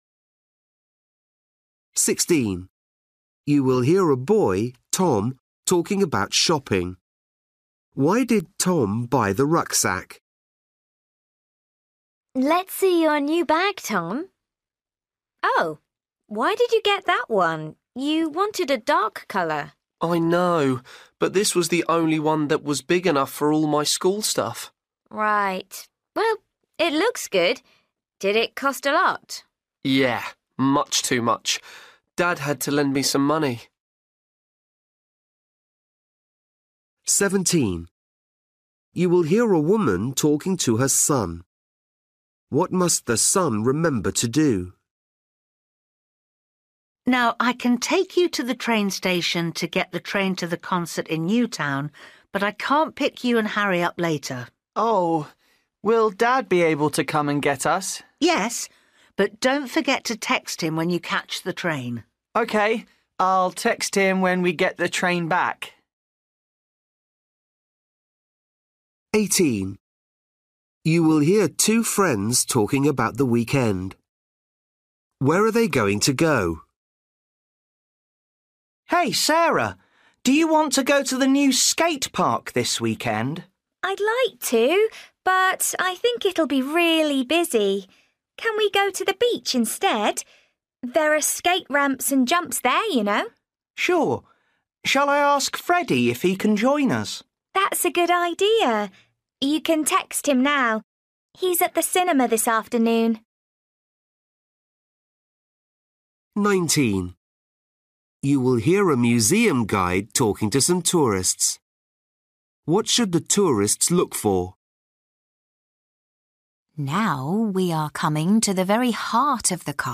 Listening: everyday short conversations
17   You will hear a woman talking to her son.
18   You will hear two friends talking about the weekend.
19   You will hear a museum guide talking to some tourists.
20   You will hear a man talking to his daughter before she goes out.